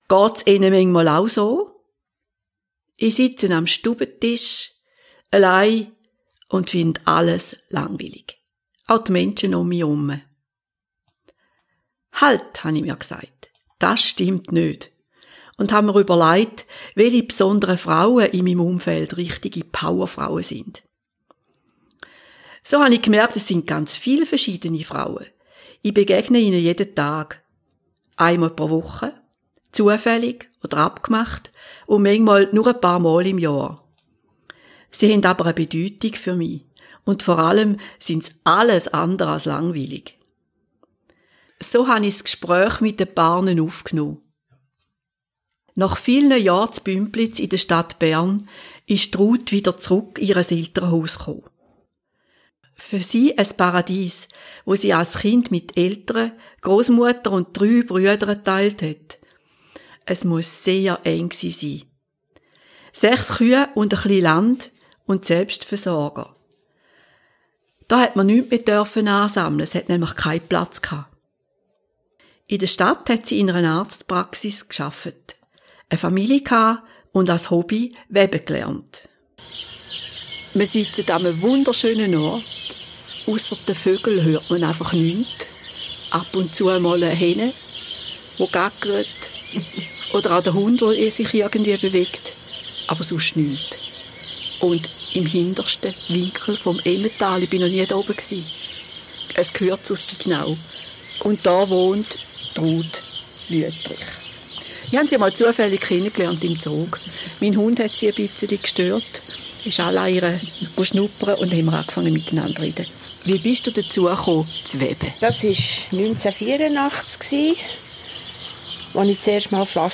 Was steckt wohl hinter der Fassade der Frau, die ich „nur“ als Mitarbeiterin kenne?Darum habe ich Frauen in meiner Umgebung interviewt und viel Neues über sie erfahren und mir ein neues Bild von ihnen gemacht.